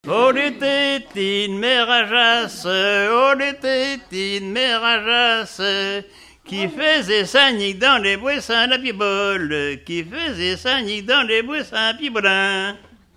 Genre laisse
Enquête Arexcpo en Vendée-Association Héritage-C.C. Herbiers
Pièce musicale inédite